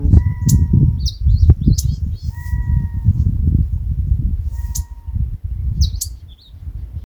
Macá Grande (Podiceps major)
Nombre en inglés: Great Grebe
Localización detallada: Estancia Buena Esperanza
Condición: Silvestre
Certeza: Observada, Vocalización Grabada